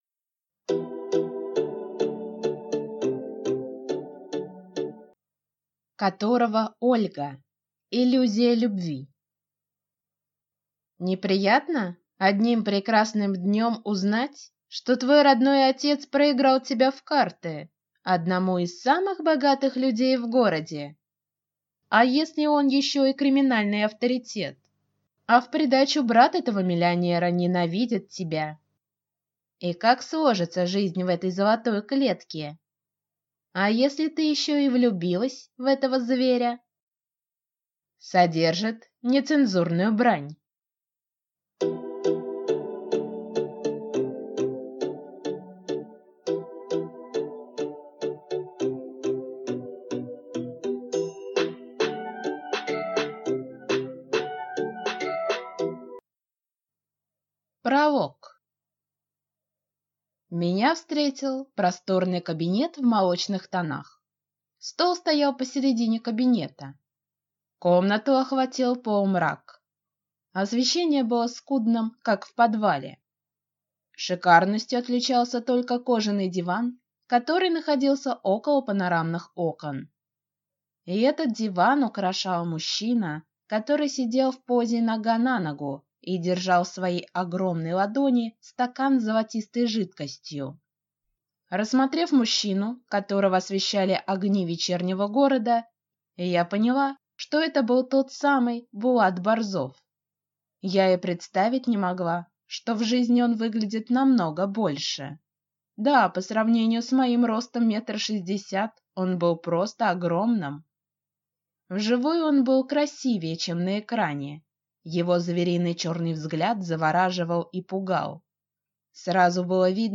Аудиокнига Иллюзия любви | Библиотека аудиокниг
Прослушать и бесплатно скачать фрагмент аудиокниги